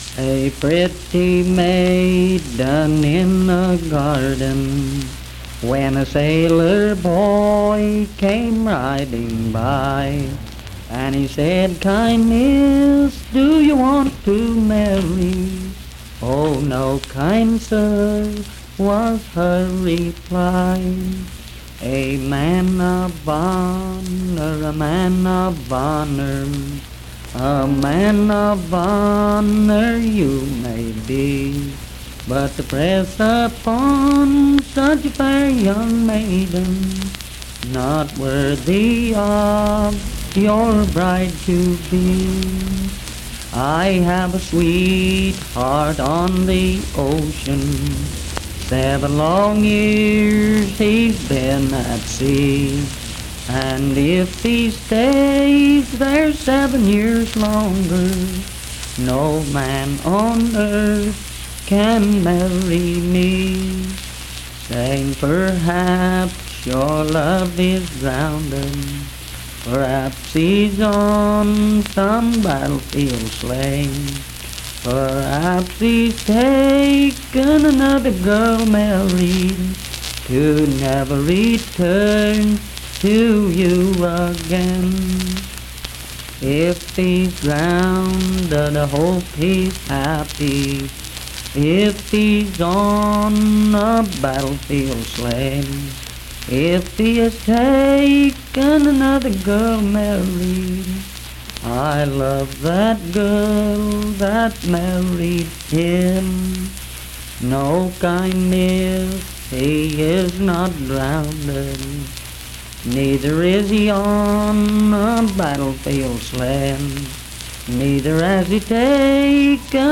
Unaccompanied vocal performance
Voice (sung)
Wirt County (W. Va.)